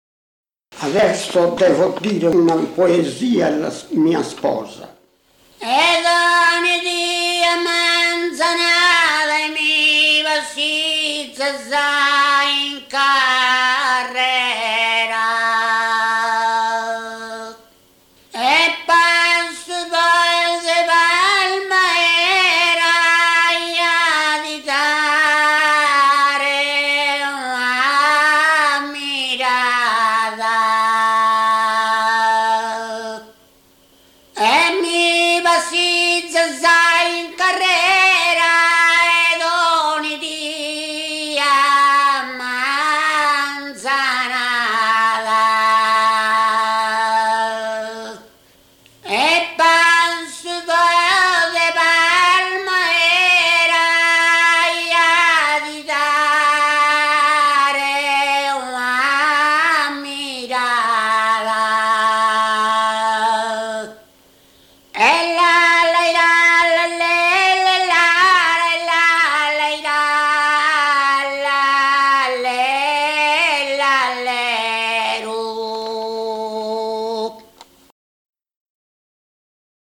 muttetu